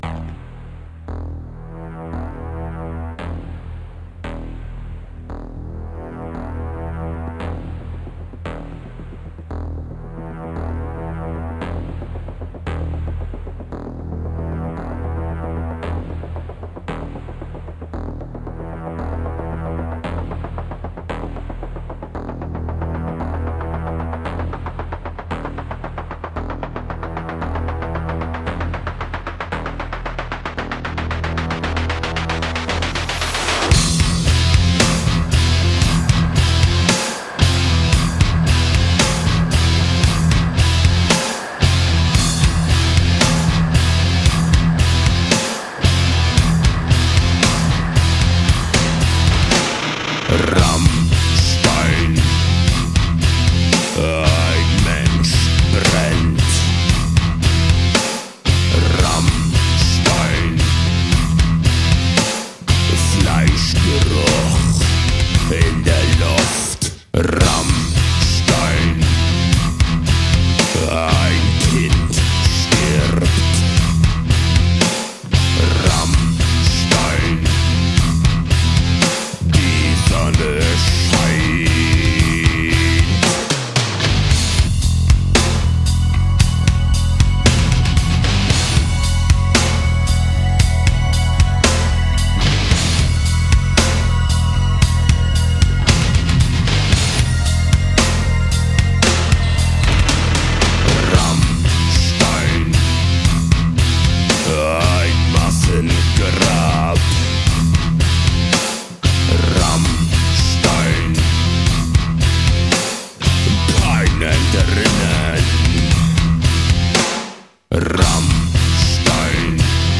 Жанр: Саундтреки / Саундтреки